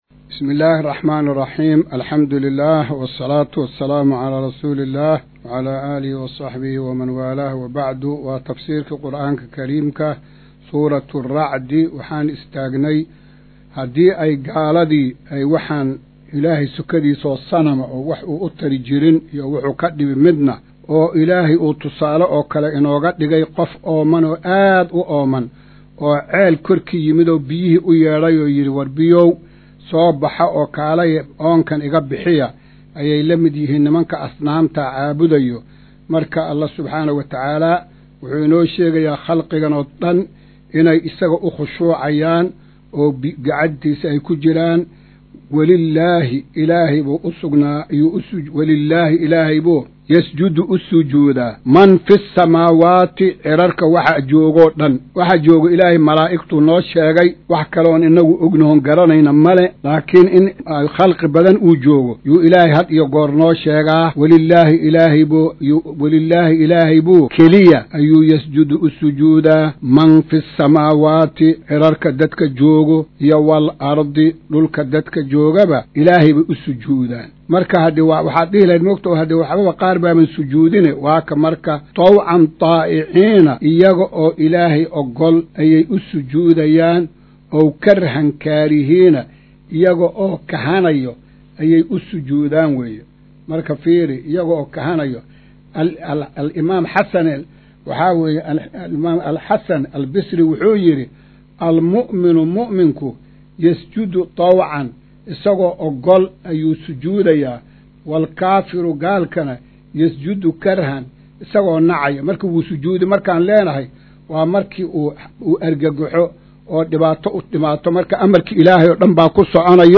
Maqal:- Casharka Tafsiirka Qur’aanka Idaacadda Himilo “Darsiga 124aad”